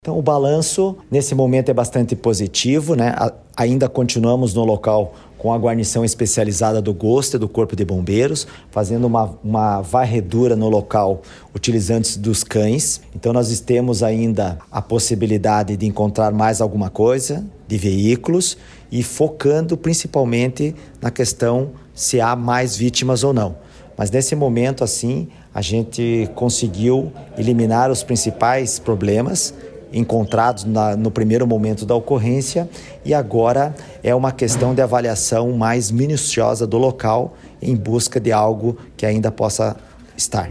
Sonora do comandante-geral do Corpo de Bombeiros, coronel Manoel Vasco, sobre o quarto dia de buscas na BR-376 | Governo do Estado do Paraná